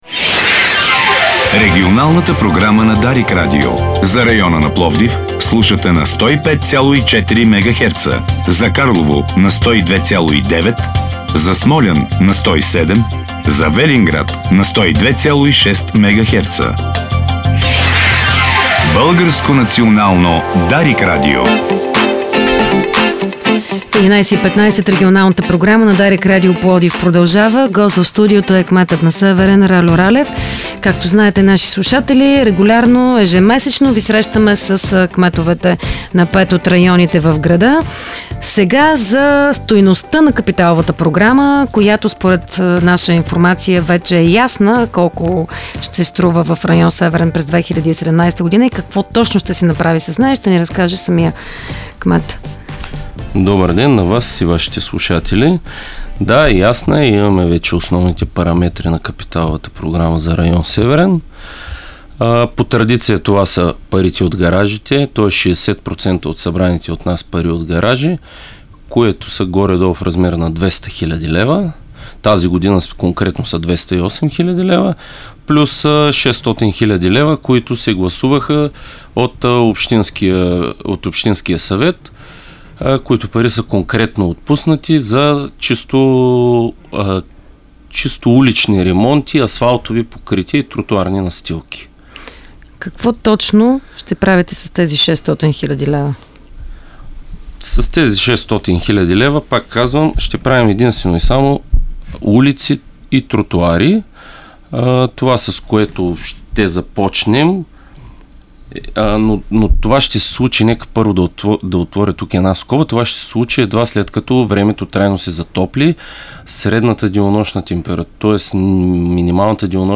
800 000 лева е стойността на капиталовата програма на пловдивския район „Северен“. Това съобщи в студиото на Дарик радио кметът Ральо Ралев.
Цялото интервю с кмета на „Северен“ Ральо Ралев чуйте от аудиото.